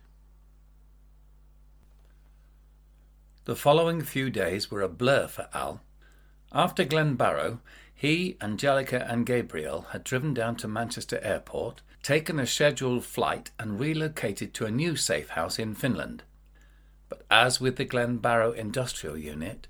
Not VERY deep but deep enough, as you will hear on the sample above.